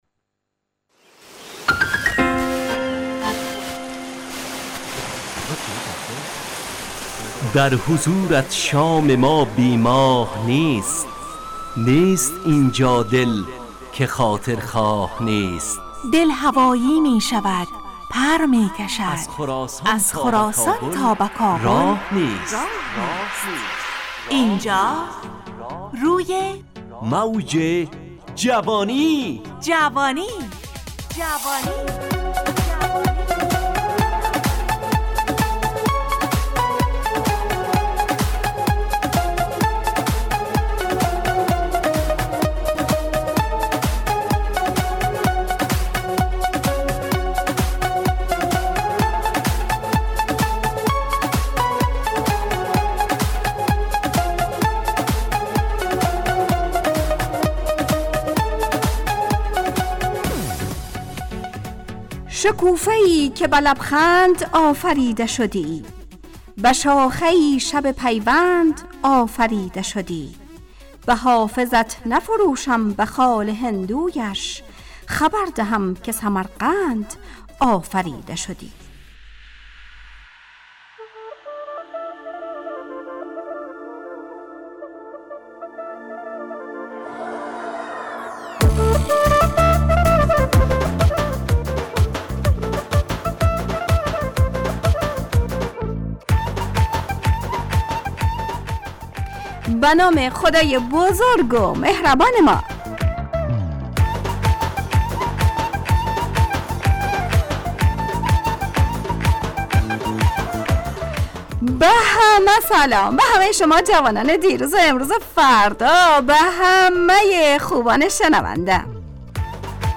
روی موج جوانی، برنامه شادو عصرانه رادیودری.
همراه با ترانه و موسیقی مدت برنامه 55 دقیقه .